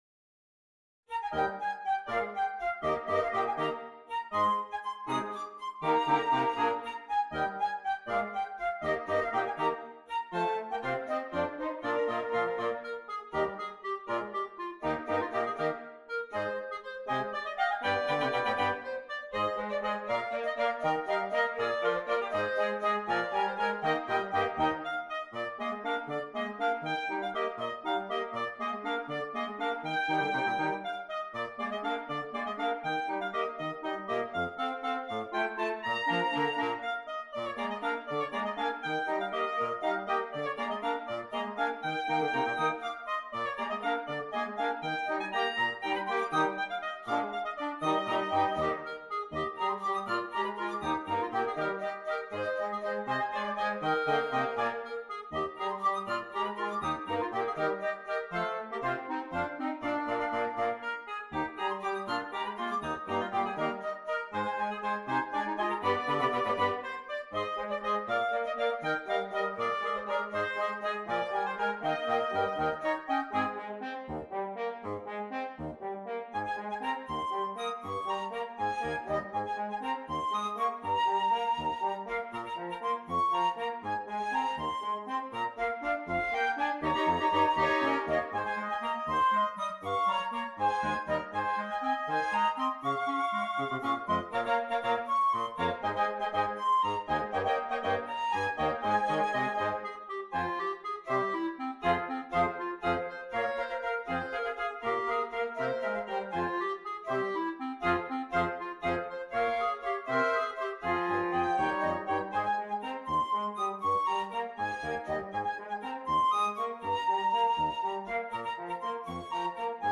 Woodwind Quintet